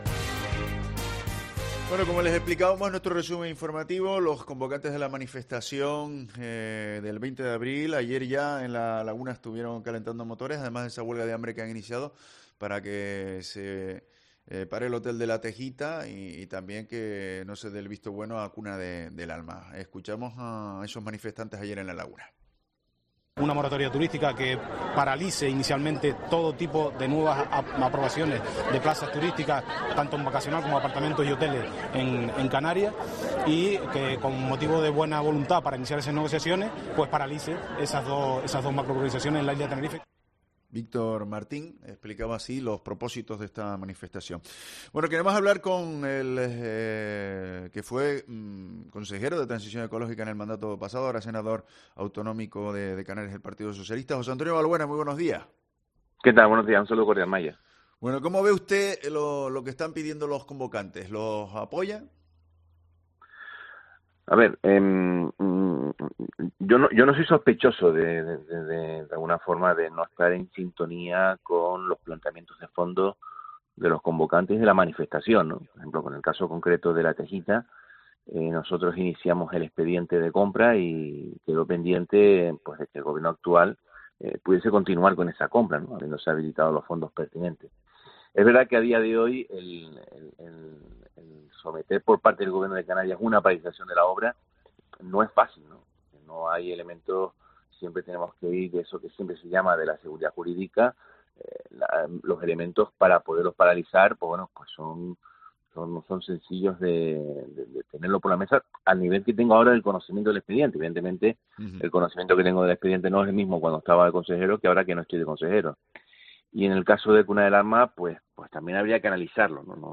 En una entrevista en COPE Canarias, Valbuena ha salido en defensa de la manifestación del 20 de abril.